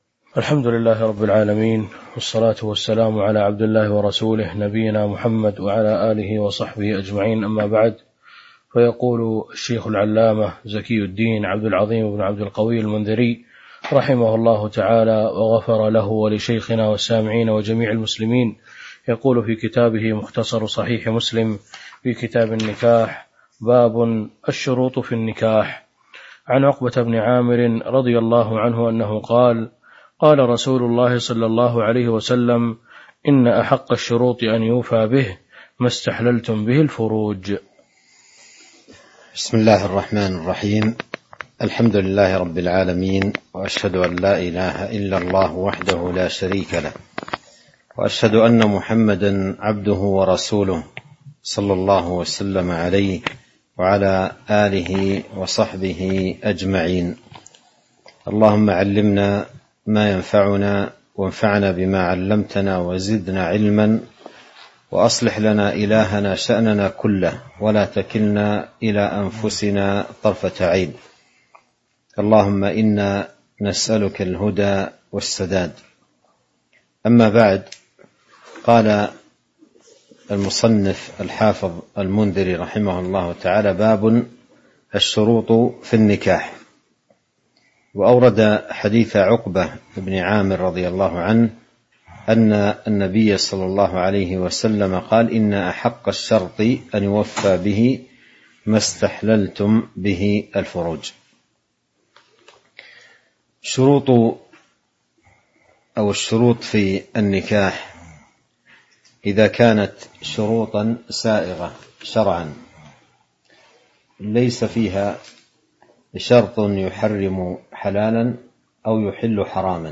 تاريخ النشر ١ محرم ١٤٤٣ هـ المكان: المسجد النبوي الشيخ: فضيلة الشيخ عبد الرزاق بن عبد المحسن البدر فضيلة الشيخ عبد الرزاق بن عبد المحسن البدر باب الشروط في النكاح (03)